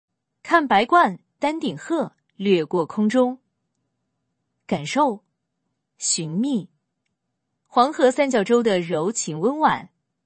抑扬顿挫，委婉洒落。通过VC输出的声音极其细腻地还原了真人的语气、情绪和声音特征。